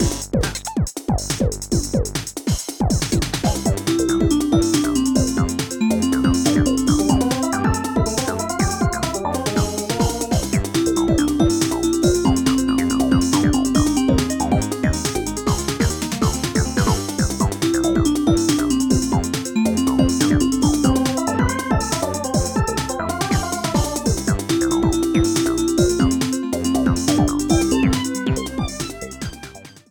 Clipped to 30 seconds and applied fade-out with Audacity